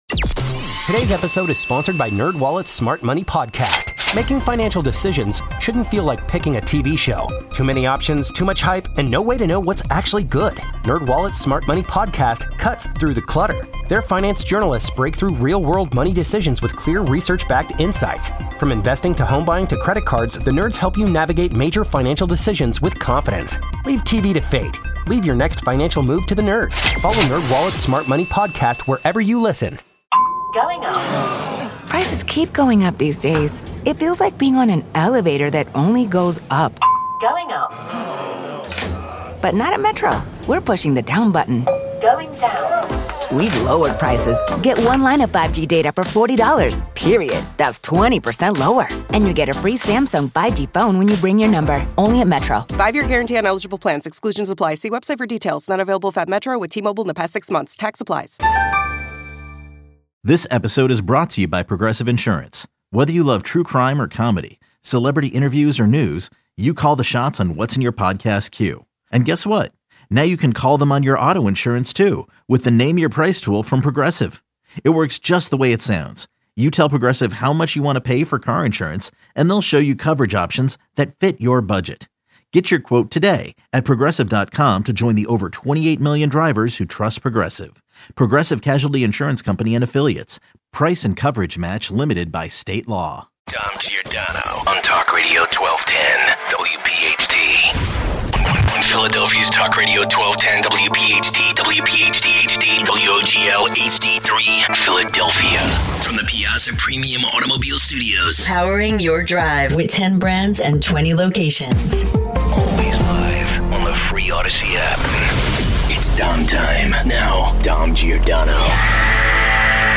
Your calls. 1240 - Continuing with the Russia-Ukraine discourse.